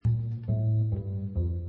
Los Gorriones de la Catedral de Ratisbona (Regensburguer Domspatzen) nos inundan con su música el estudio. ¿Qué significa el Año Jubilar de la Misericordia? Nos lo explica Monseñor Ginés García Beltrán.